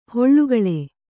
♪ hoḷḷugaḷe